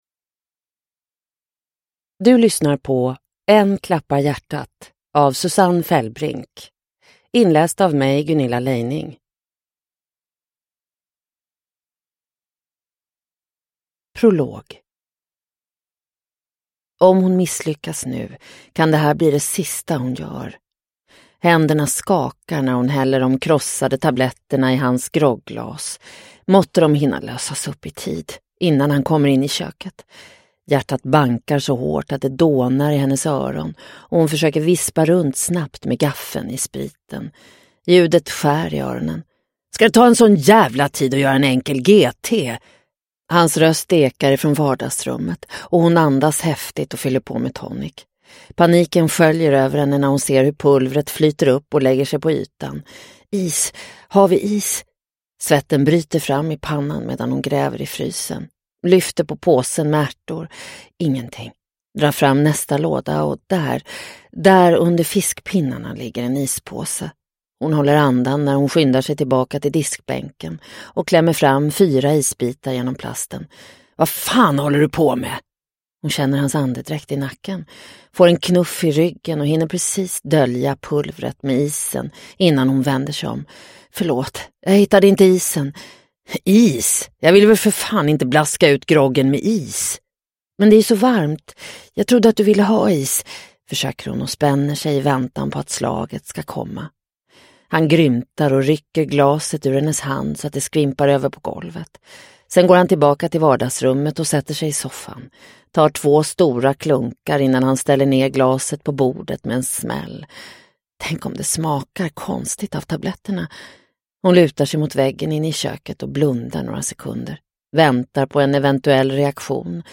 Än klappar hjärtat – Ljudbok – Laddas ner